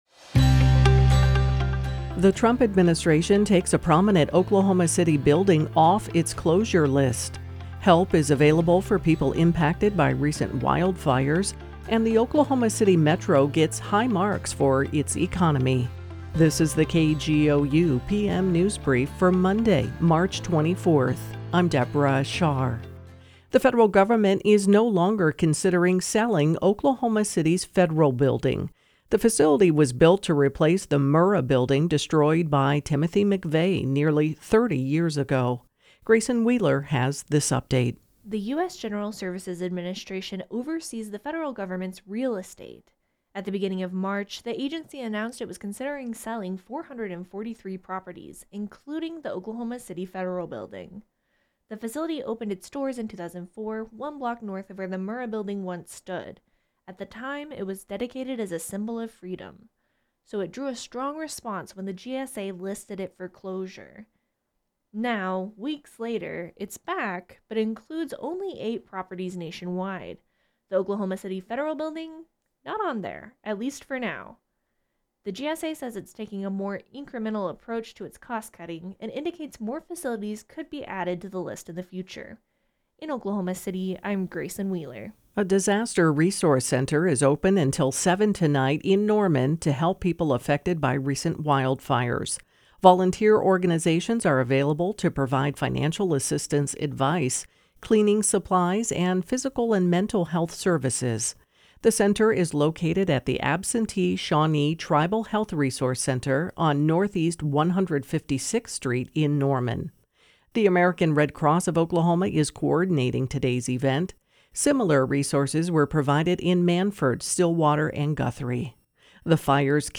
Latest Oklahoma news from KGOU - Your NPR Source … continue reading 207 episode # Daily News # Politics # News # KGOU # KGOU Radio